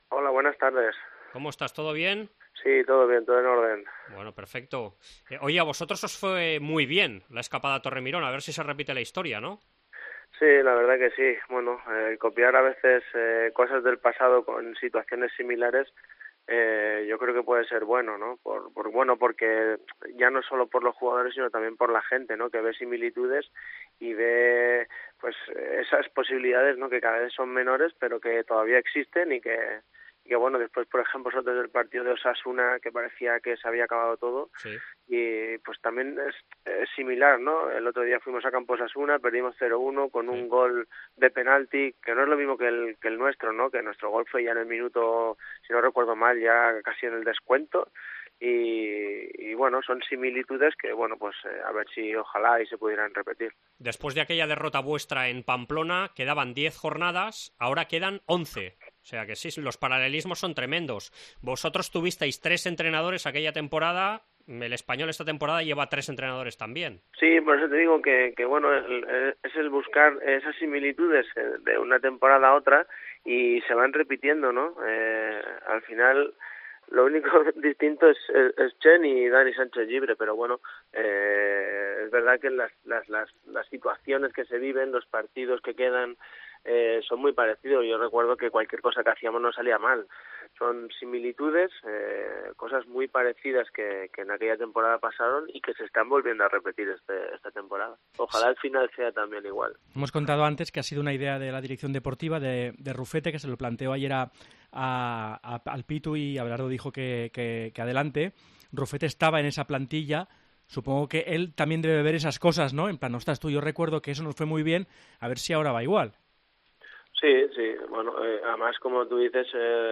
AUDIO: Entrevista con el ex jugador del RCD Espanyol, retirado el año pasado, que ve paralelismos con la salvación in extremis de aquel año